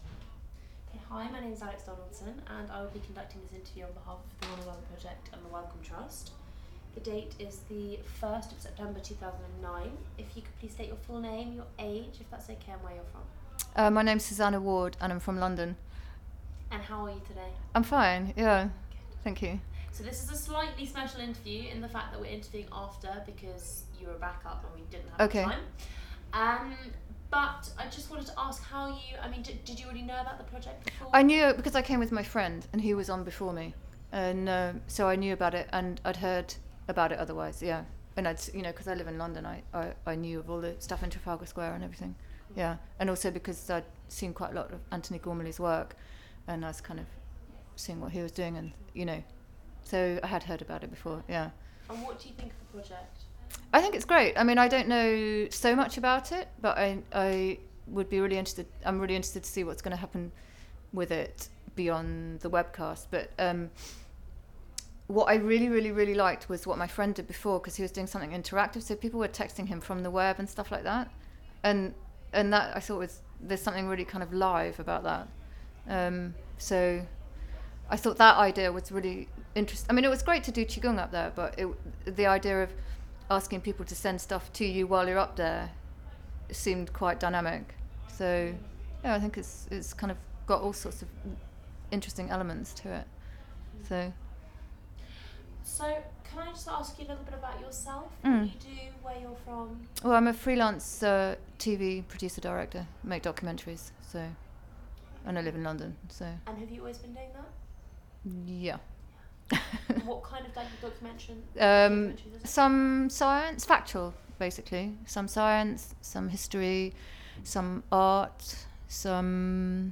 Audio file duration: 00:06:30 Format of original recording: wav 44.1 khz 16 bit ZOOM digital recorder.
These recordings are part of the One & Other interview series that has been licensed by the Wellcome Trust for public use under Creative Commons Attribution-non commercial-Share Alike 3.00 UK.